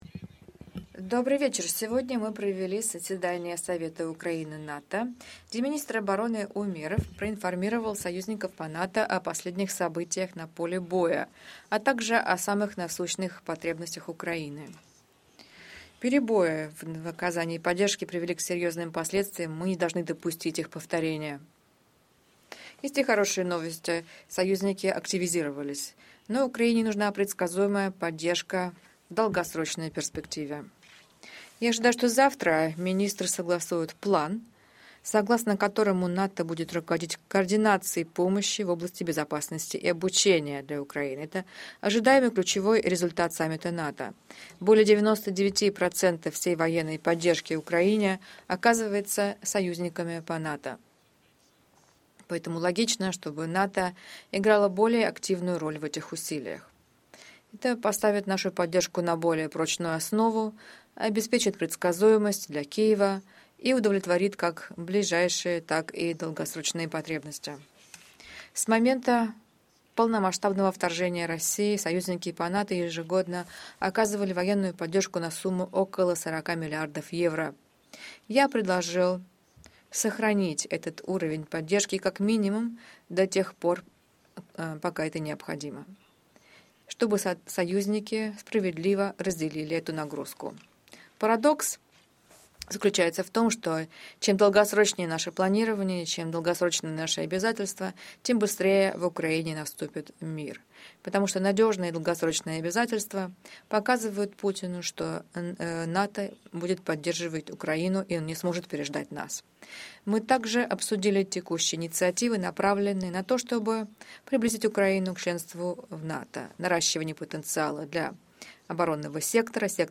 Press conference
by NATO Secretary General Jens Stoltenberg following the first day of the meetings of NATO Defence Ministers